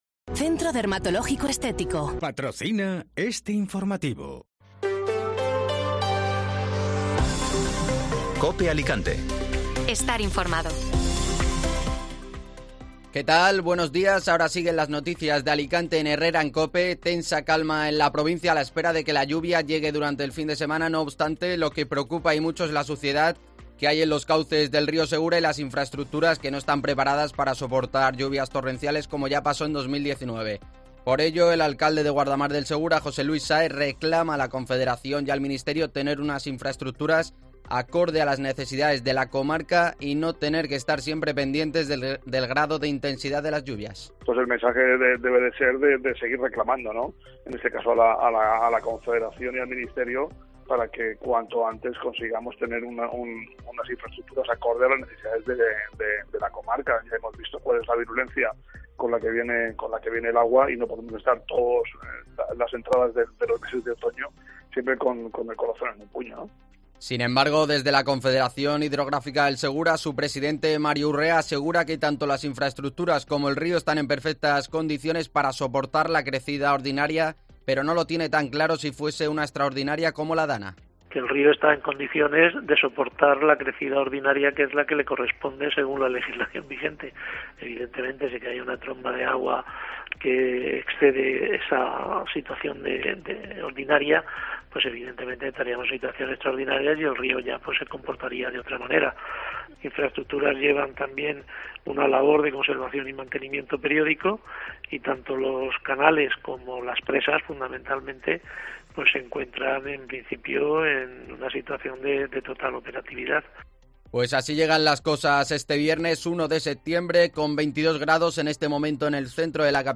Informativo Matinal (Viernes 1 de Septiembre)